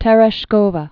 Te·resh·ko·va
(tĕrĕ-shkōvə, tyĭ-ryĭ-), Valentina Vladmirovna Born 1937.